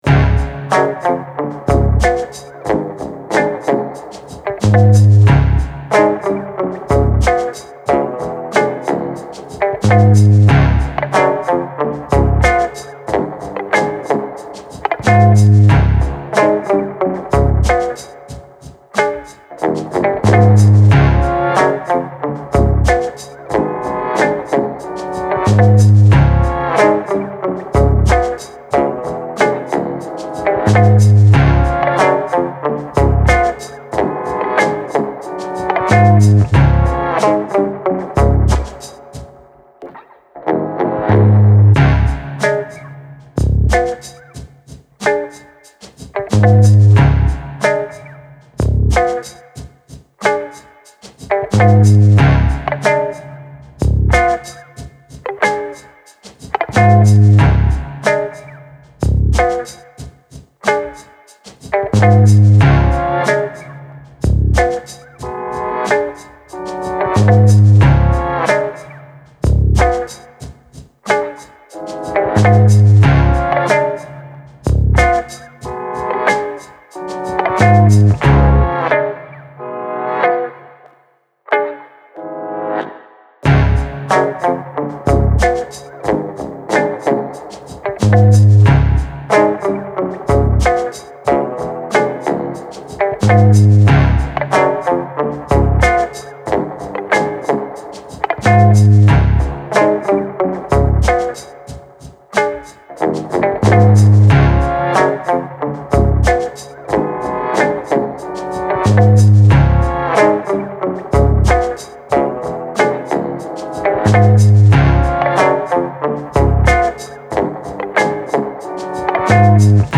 Spooky low slung bass with oddball accents.